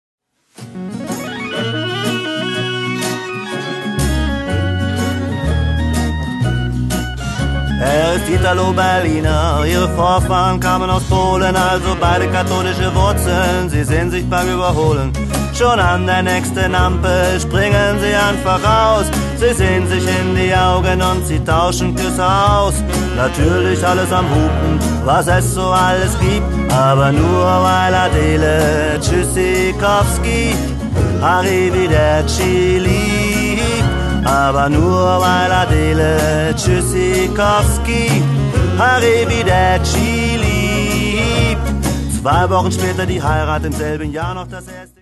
Ich mache deutschsprachige Lieder zur Gitarre.